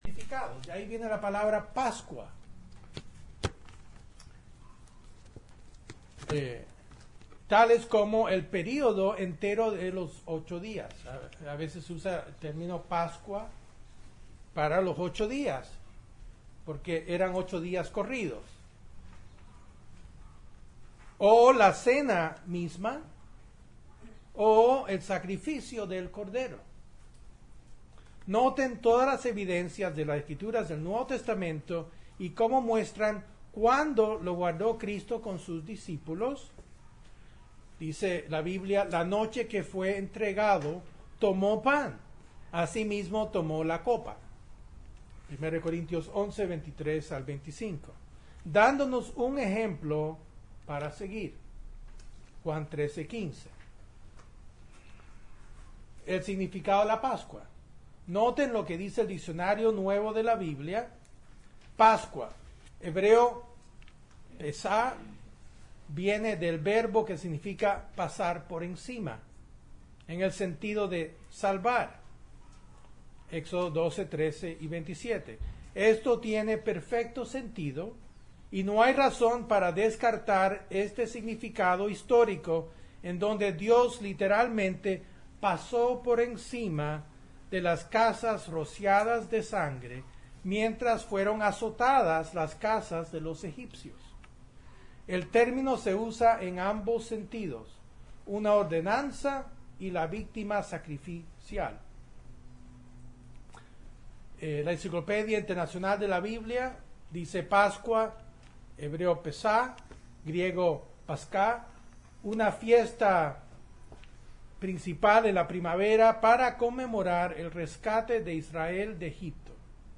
Un sermon en La Pascua
Spanish language sermon on the Passover